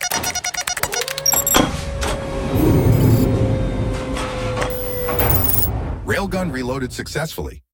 reload.ogg